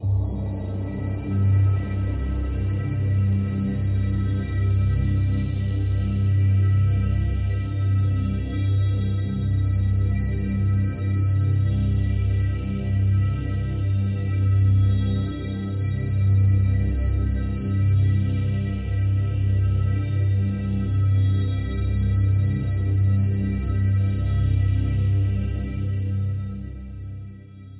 现场太空垫05
描述：活Krystal Cosmic Pads